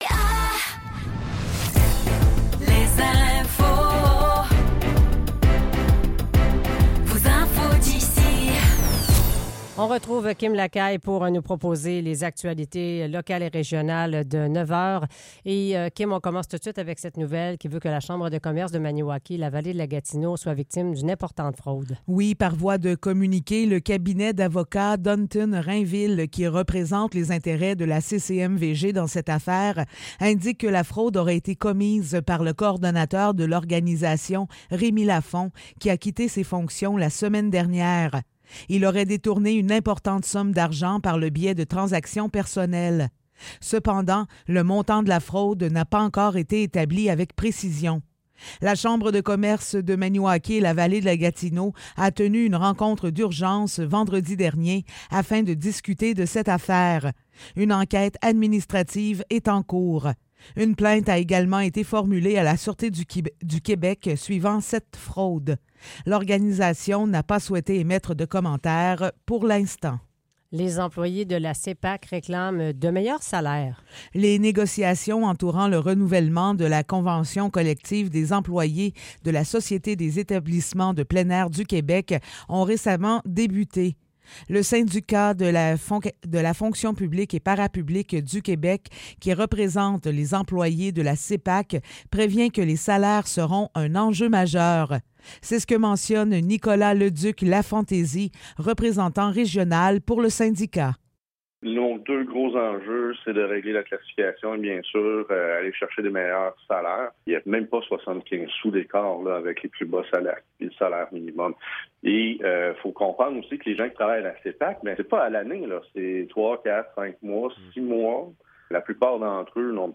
Nouvelles locales - 30 juillet 2024 - 9 h